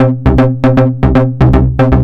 TSNRG2 Bassline 002.wav